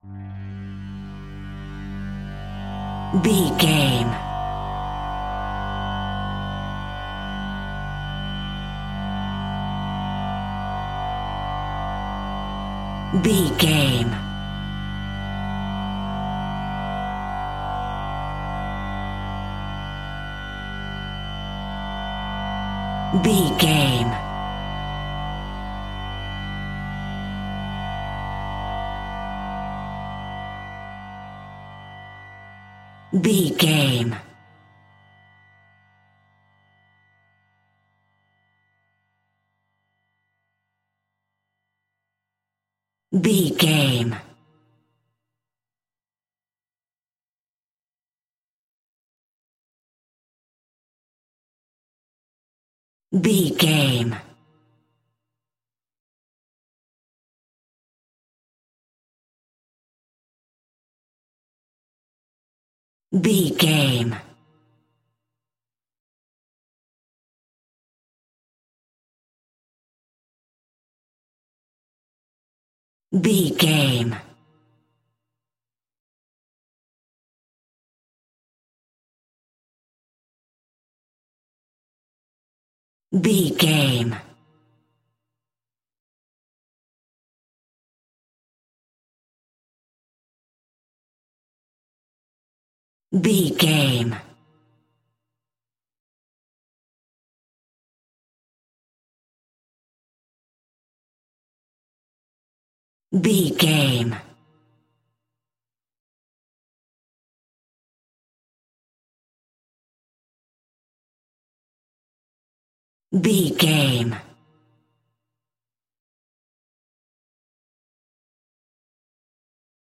Synth Horror.
In-crescendo
Atonal
scary
ominous
dark
suspense
haunting
eerie
Synth Pads
Synth Strings
synth bass